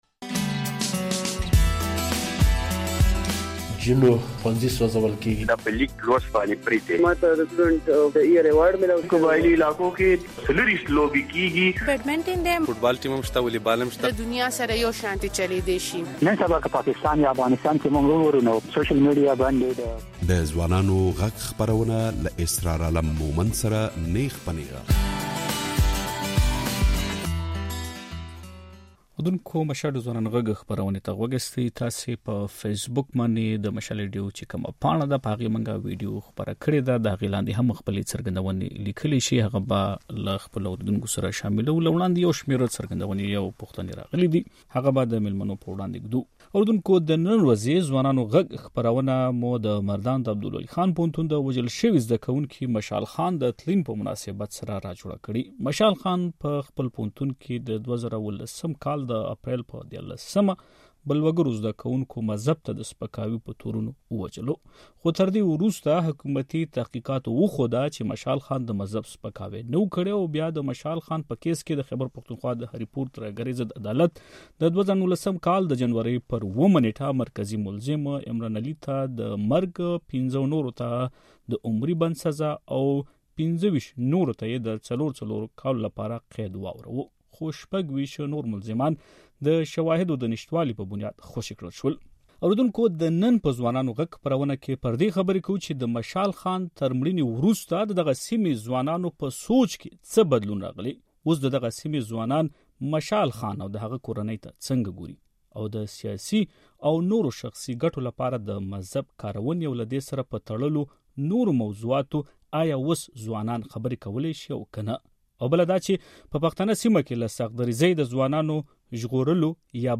بلخوا په خپرونه کې شامل حکومتي استازی وايي چې ياد اصلاحات به کوي.